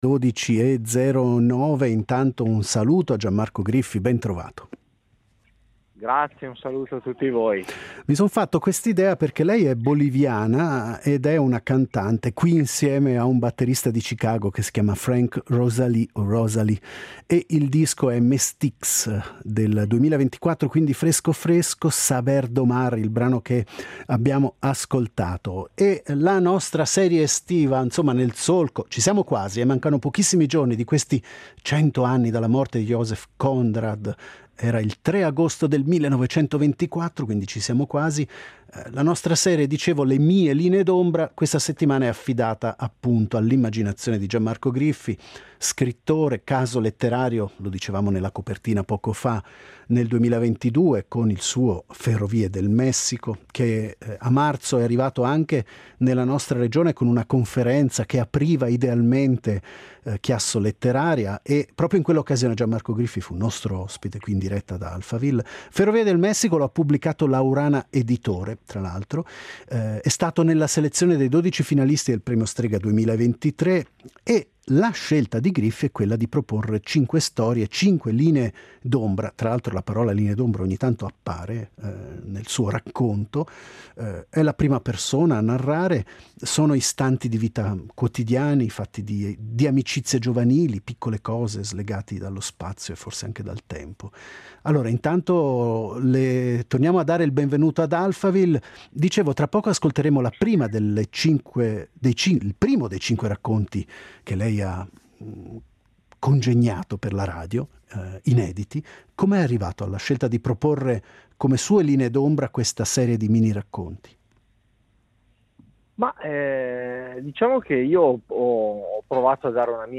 Intervista al romanziere italiano